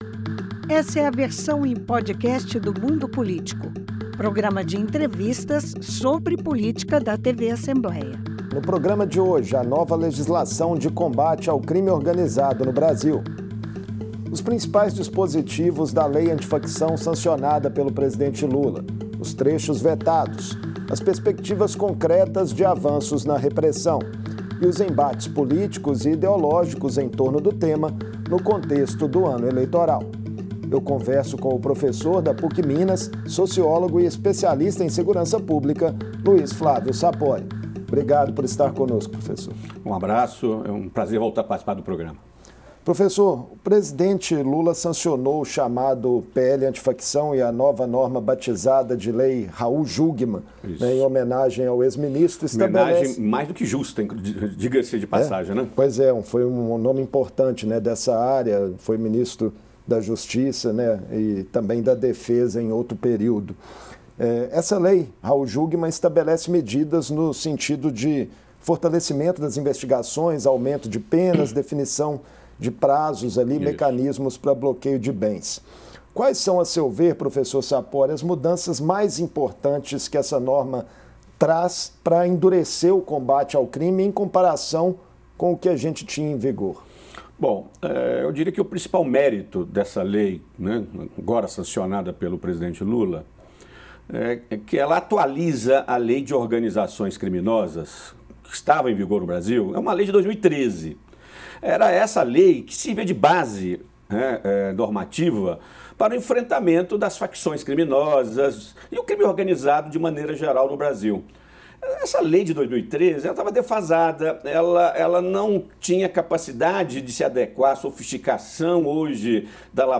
A lei antifacção foi sancionada pelo presidente Lula com dois vetos, após aprovação final na Câmara dos deputados em fevereiro. A nova lei endurece o combate ao crime organizado. Em entrevista